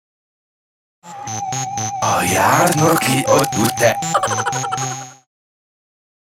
Genere: rap
Rovesciato